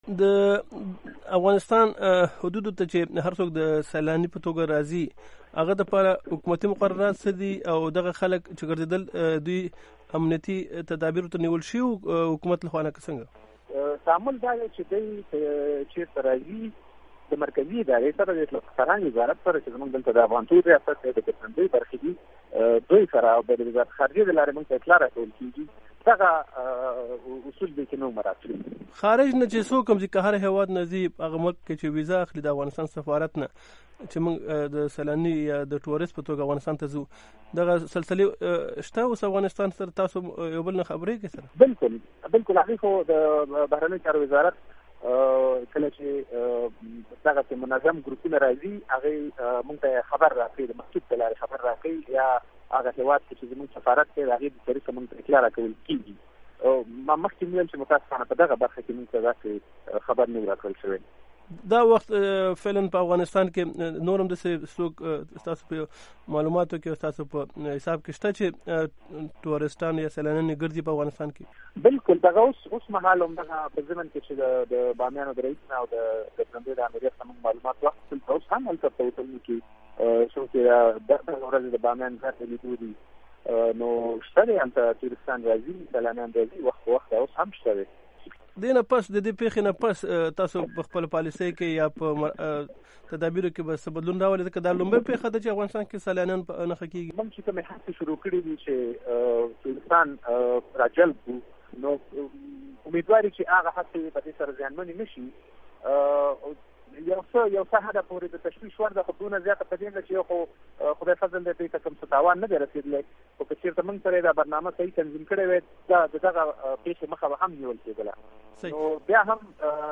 د اطلاعاتو او فرهنګ وزارت د ګرځندوۍ او اداري معين ښاغلي زردشت شمس سره مرکه